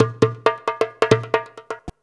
Index of /90_sSampleCDs/USB Soundscan vol.56 - Modern Percussion Loops [AKAI] 1CD/Partition C/17-DJEMBE133
133DJEMB05.wav